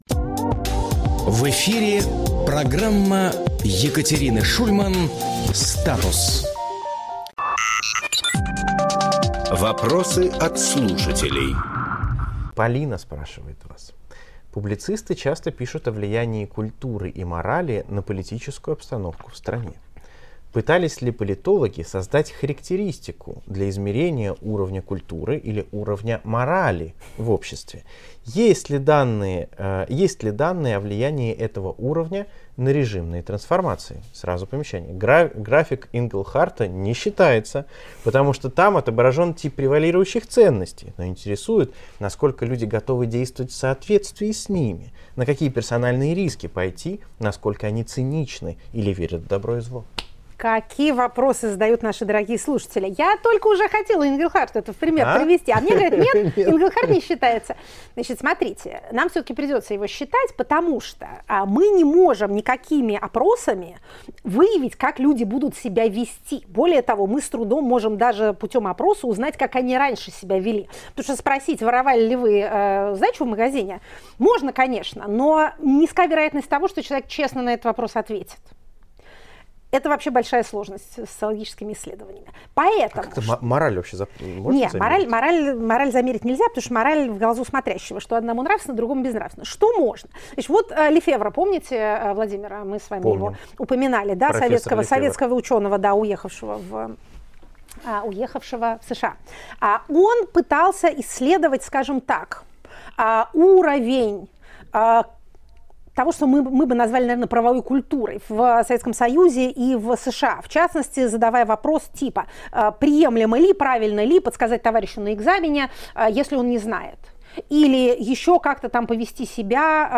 Екатерина Шульманполитолог
Фрагмент эфира от 10.12.24